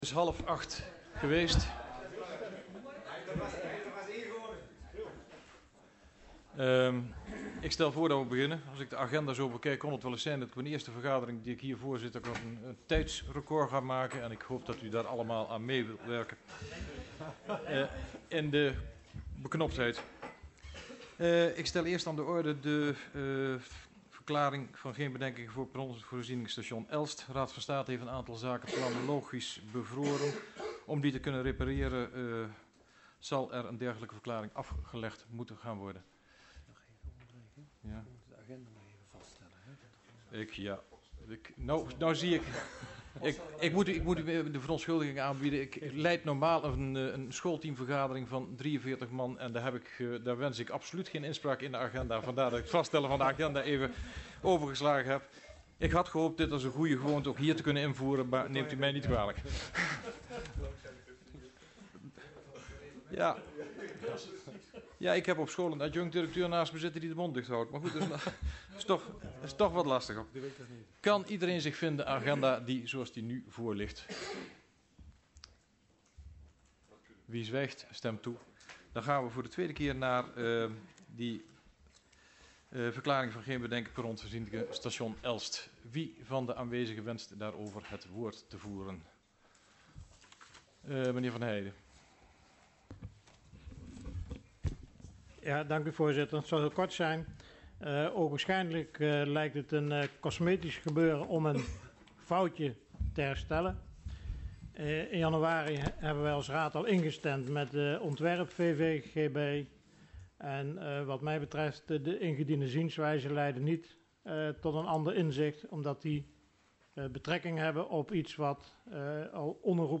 Agenda OverBetuwe - Voorronde Hal dinsdag 3 juni 2014 19:30 - 22:00 - iBabs Publieksportaal
Locatie Hal, gemeentehuis Elst Voorzitter dhr G.J.M. op de Weegh Toelichting De raad heeft op 20 mei besloten agendapunt 1 van de agenda te verwijderen. Voorbereidende vergadering verklaring van geen bedenkingen voor perronvoorzieningen station Elst